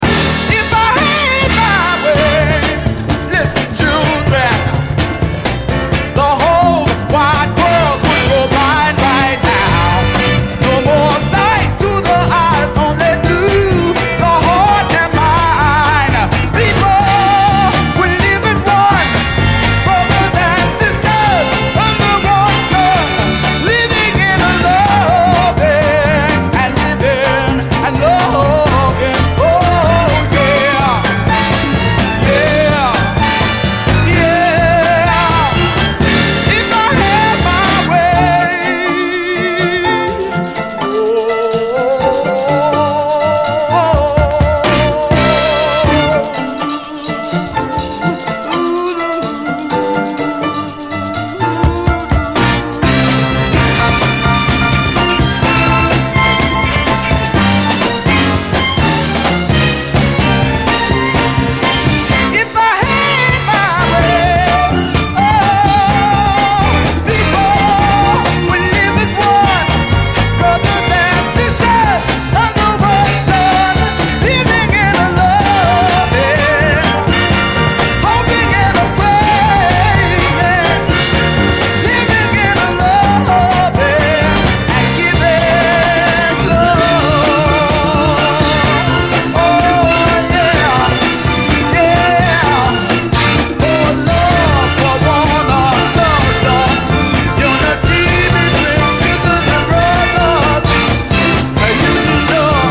SOUL, 70's～ SOUL, 7INCH
スリリングに駆け抜ける70’Sファンキー・ノーザン・ソウル45！